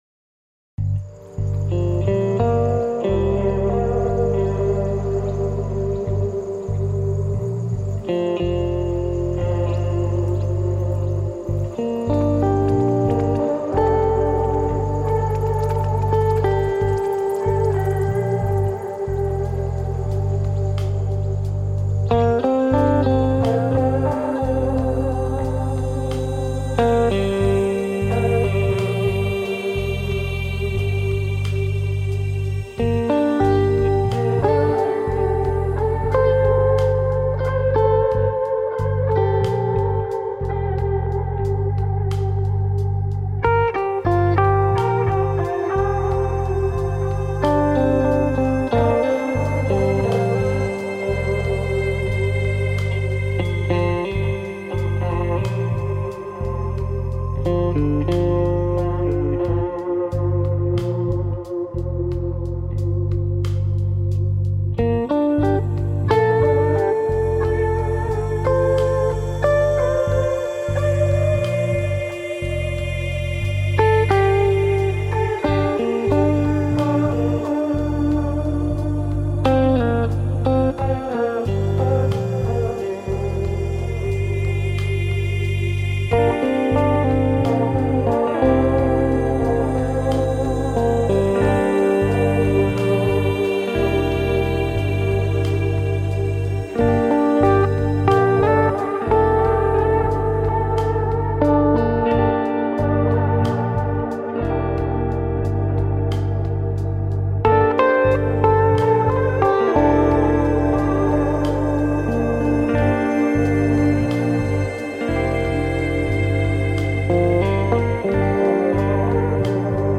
This one has 90 minutes of music you’ll like.